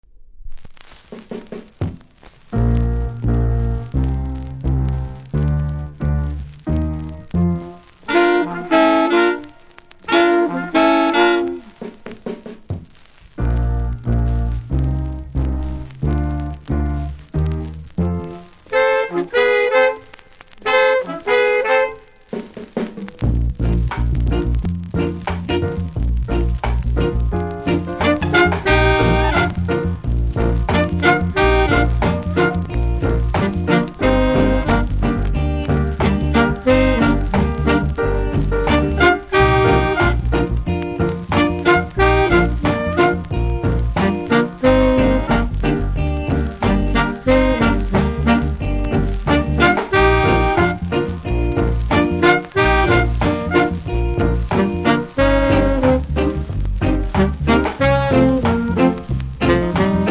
キズによるノイズなのかプレス起因のノイズなのか区別付きませんが全体的にノイズ感じますので試聴で確認下さい。